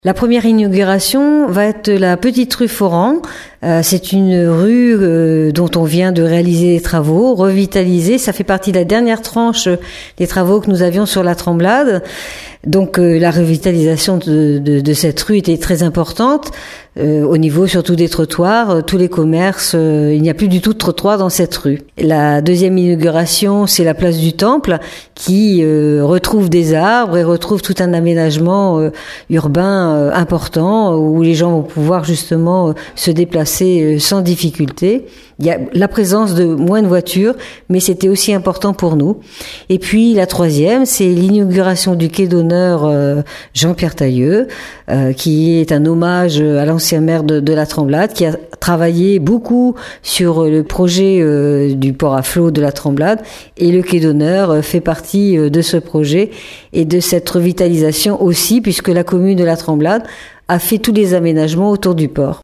La maire de la commune Laurence Osta-Amigo nous en parle :